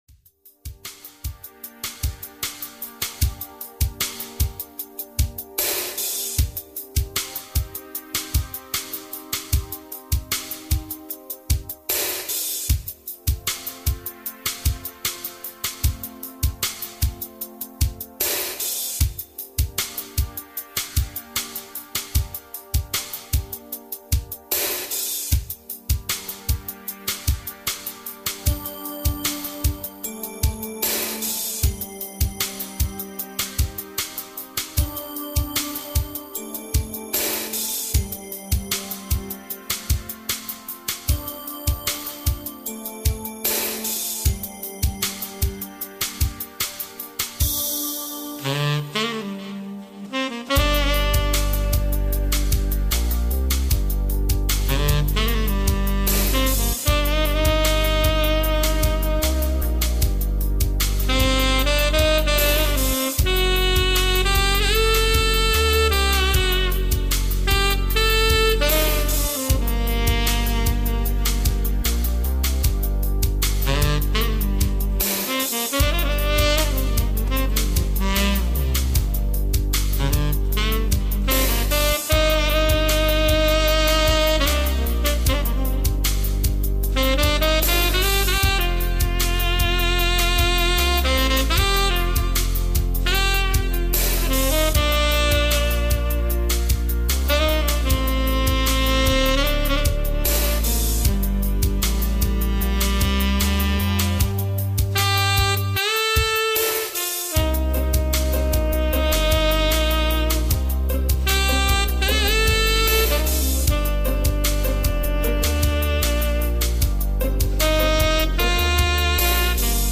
Всем доброго дня! настроение в музыке без слов... дорога в никуда